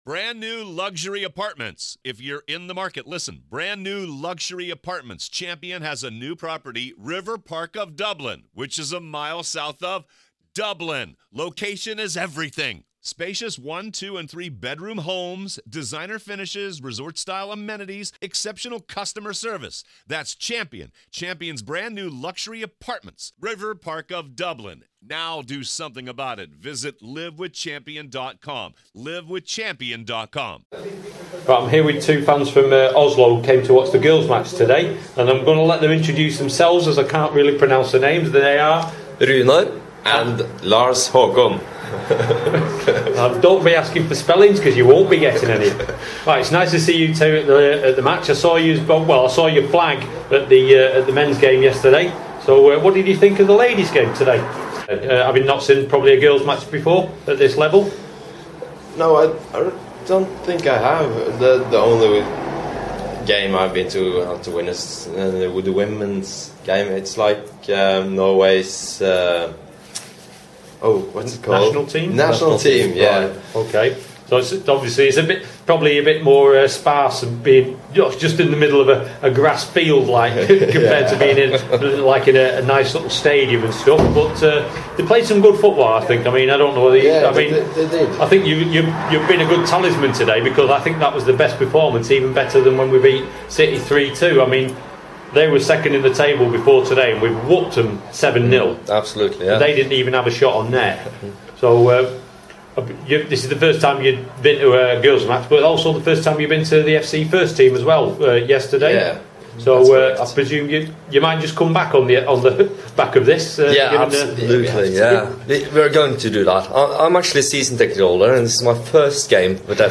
Post Match Interview - Two Norwegians - AFC Oldham Ladies (h)